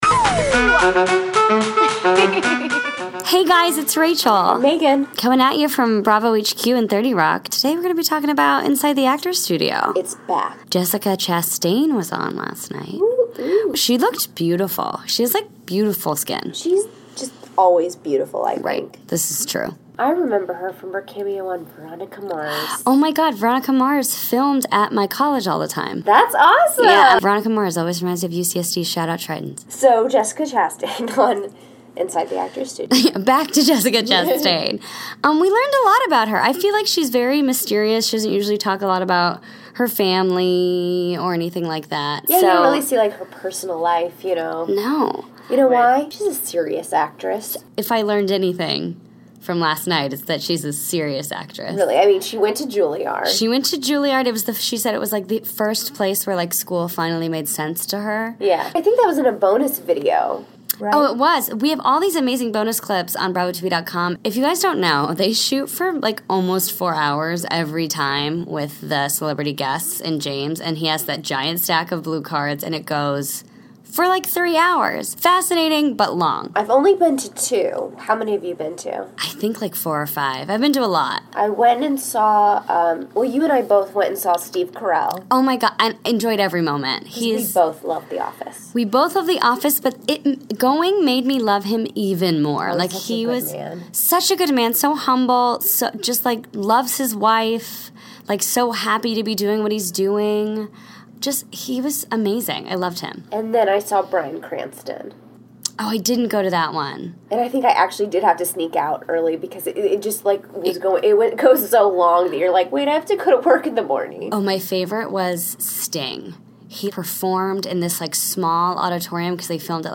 Thursday, December 22, 2016 - From Bravo HQ in New York City, we discuss everything we learned about Jessica Chasten from Inside the Actors Studio, including that she’s a vegan, she loves Shakespeare, and hates school, unless it’s Julliard. Plus - find out what it’s like to attend a taping.